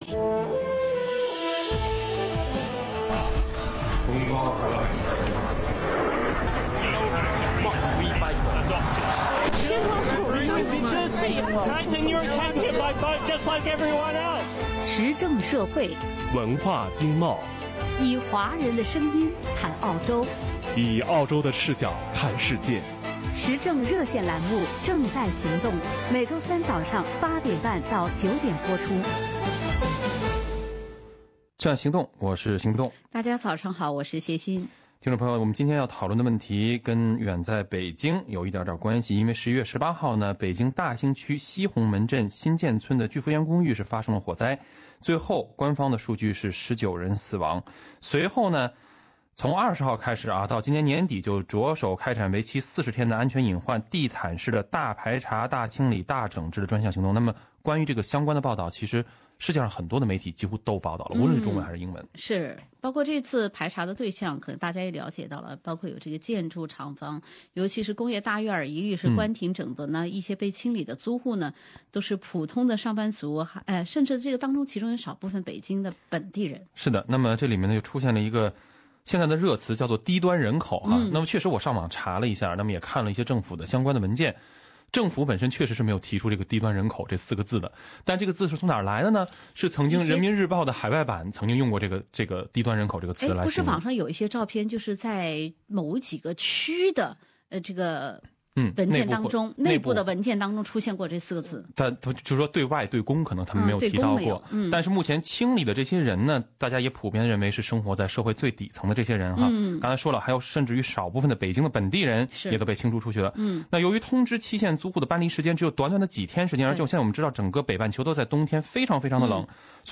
以下是本期《正在行动》节目中热线听众的部分观点：